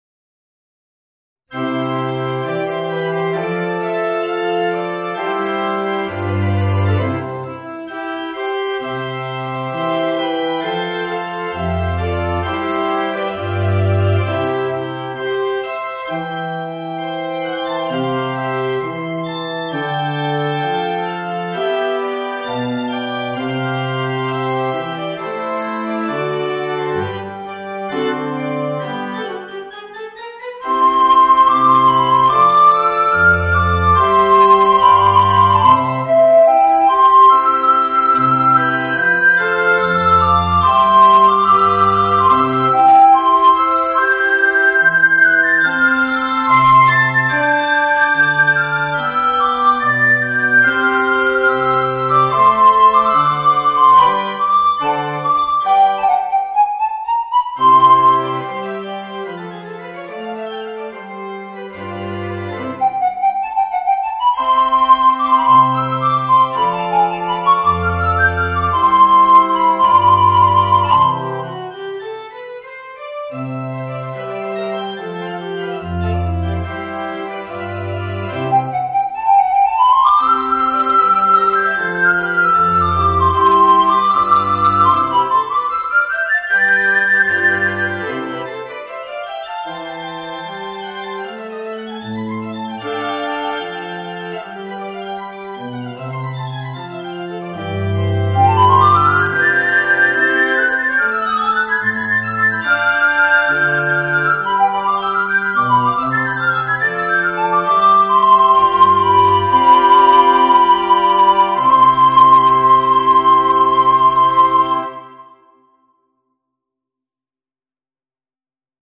Voicing: Soprano Recorder and Piano